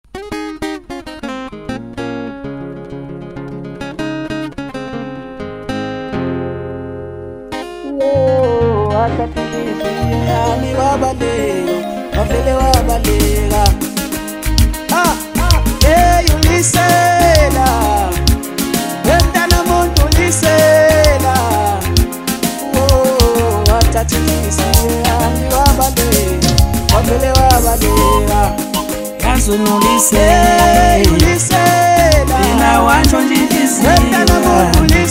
Home » Maskandi